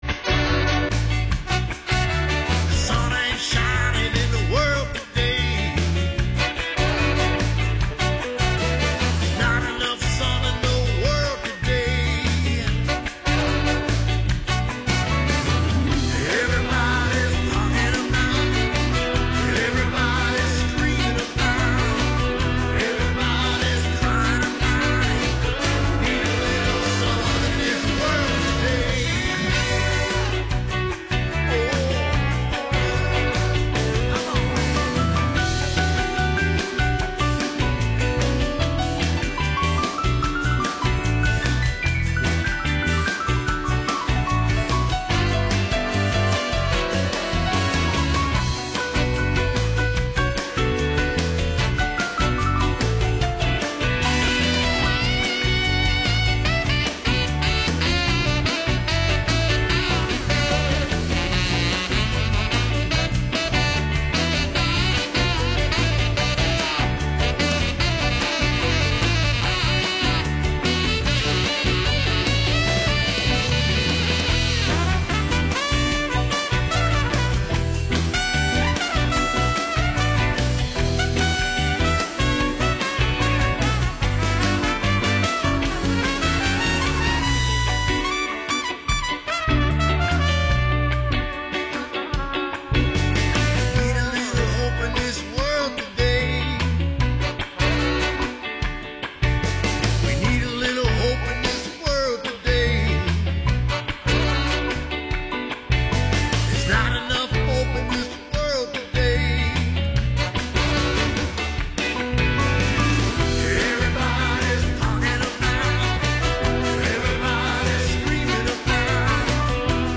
We’ll play some of them on tonight’s show.